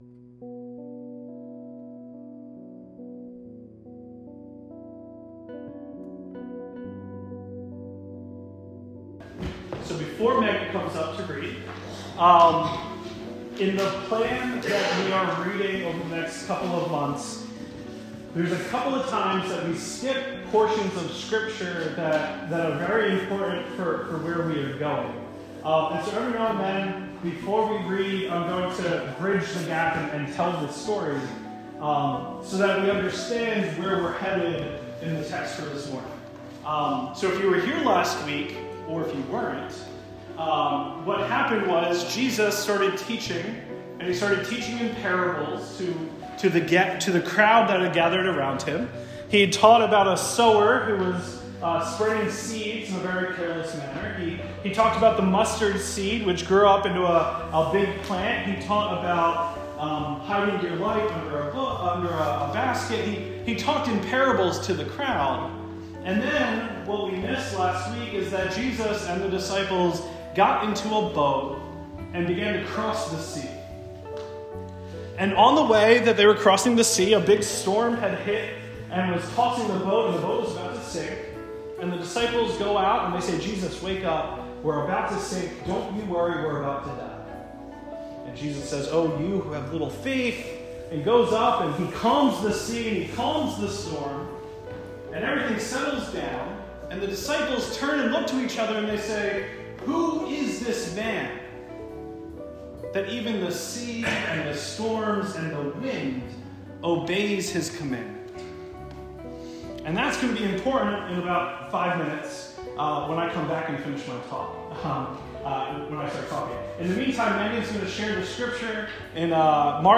Sermon-1-27-20.mp3